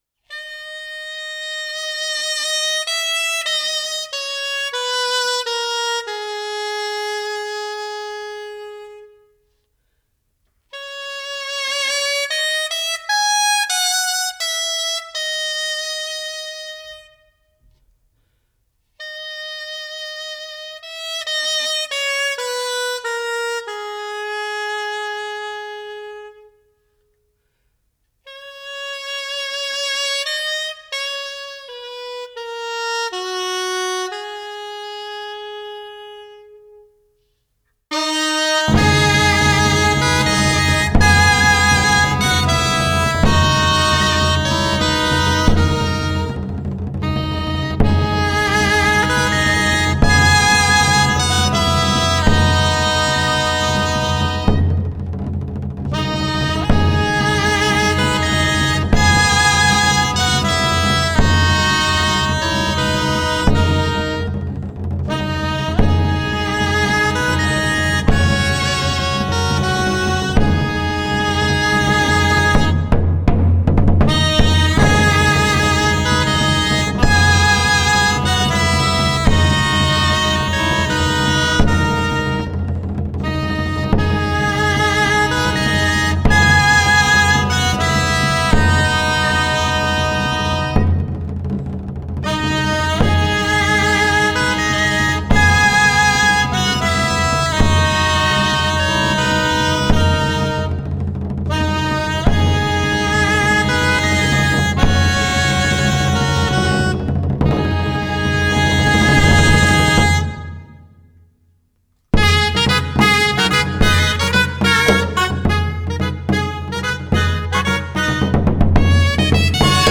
23. MOIXIGANGA DE TARRAGONA So Nat grallers